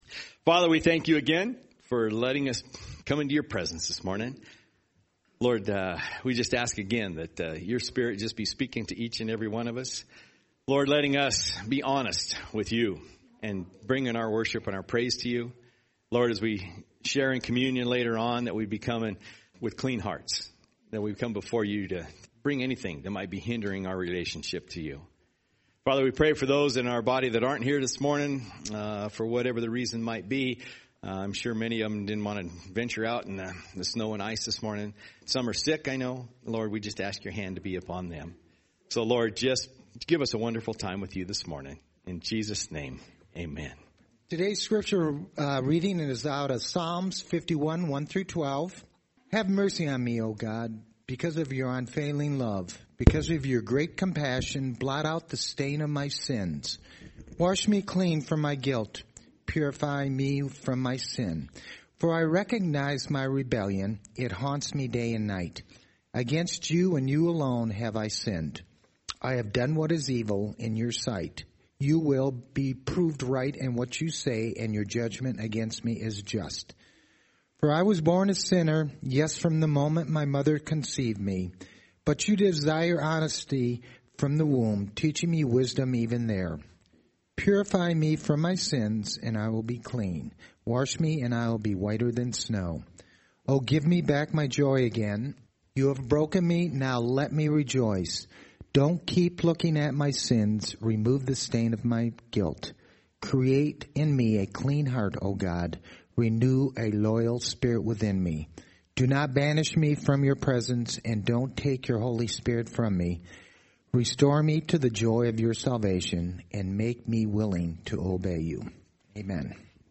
Scripture Reading
Fellowship Sunday, Communion & Missionary Updates